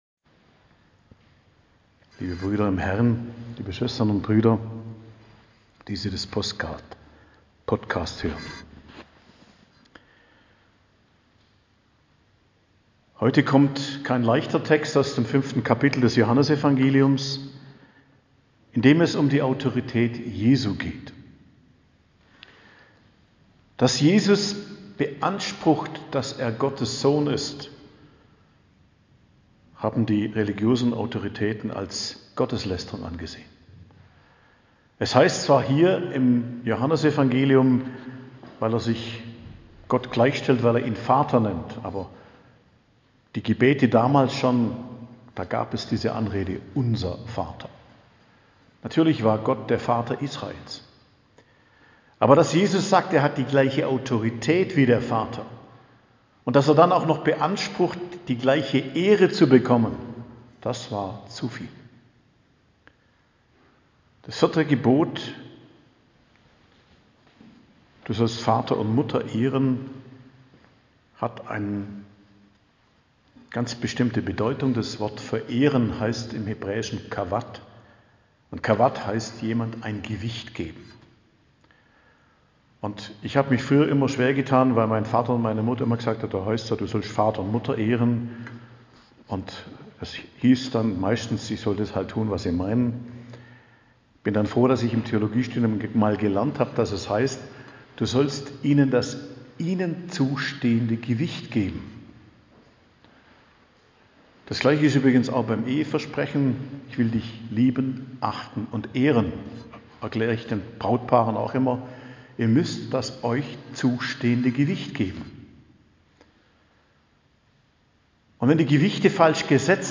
Predigt am Mittwoch der 4. Woche der Fastenzeit, 30.03.2022